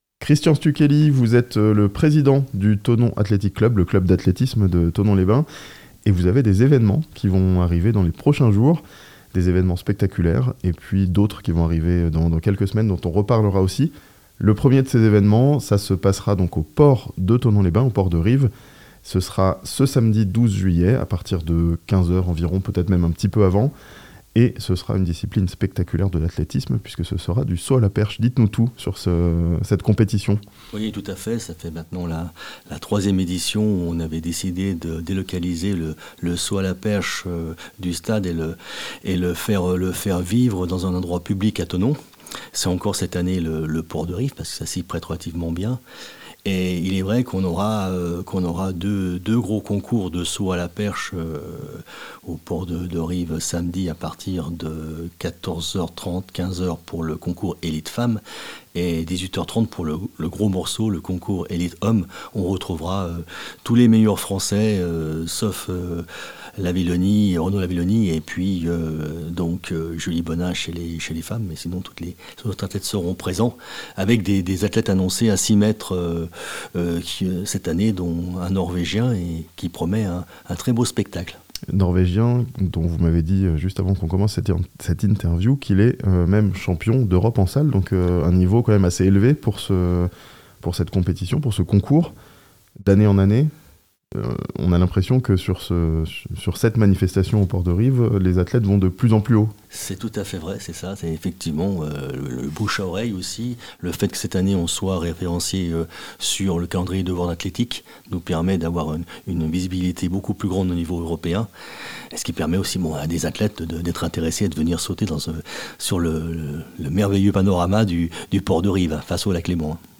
Saut à la perche au port, meeting international...l'athlétisme à l'honneur, à Thonon ce weekend (interview)